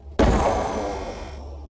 explosion_1.wav